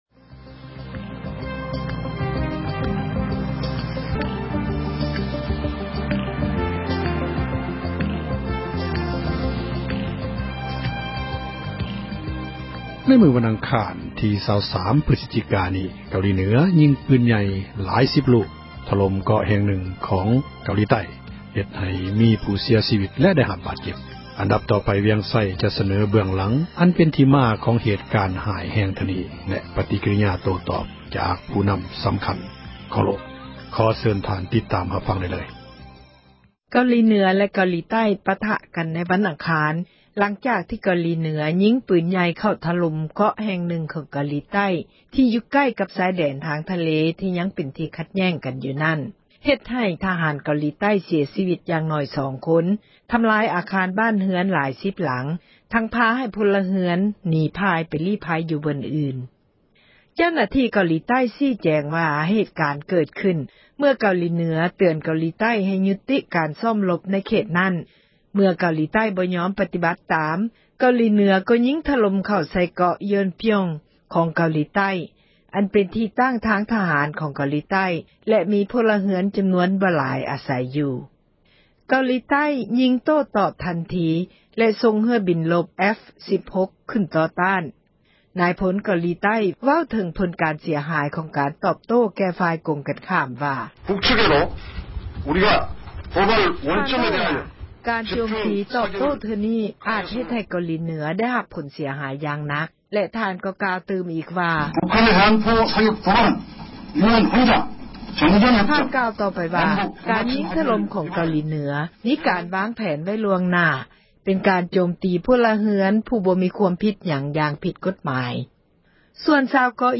ການປະທະກັນຣະຫວ່າງ ເກົາຫລີໃຕ້ແລະເໜືອ — ຂ່າວລາວ ວິທຍຸເອເຊັຽເສຣີ ພາສາລາວ